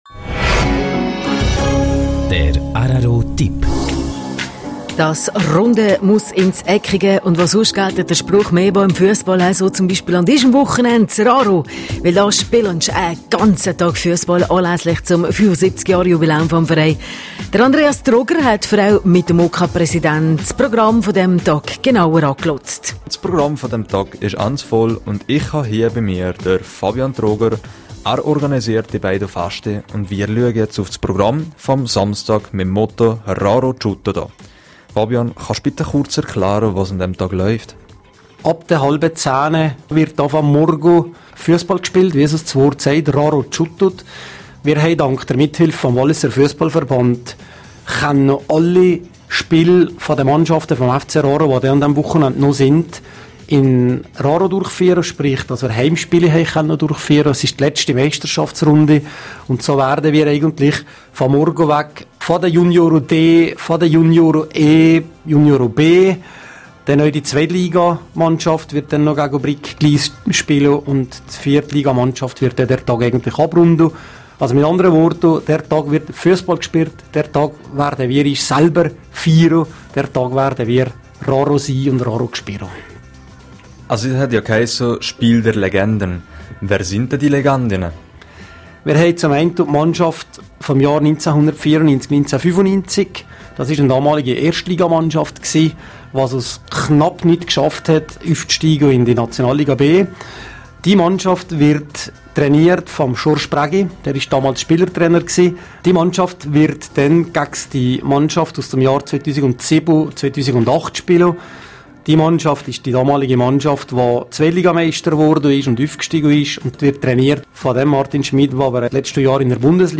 Ganzes Interview zum Festival